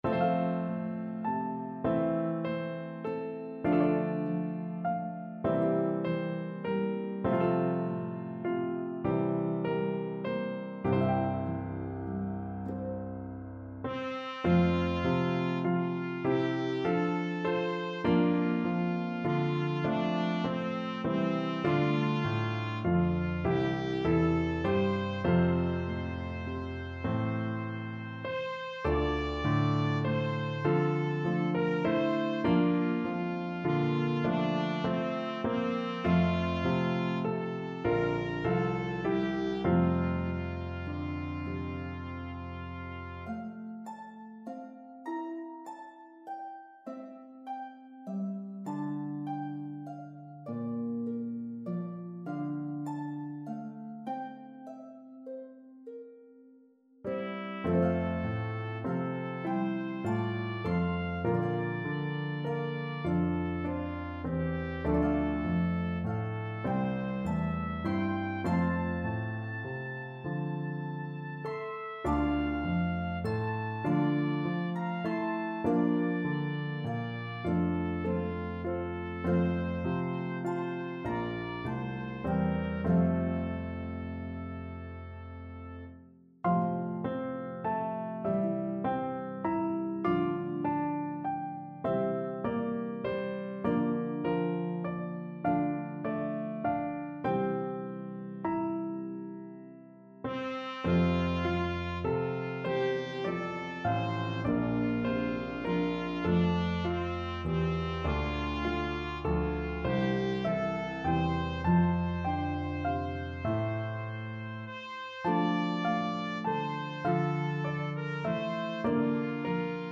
Harp, Piano, and Trumpet in B-flat version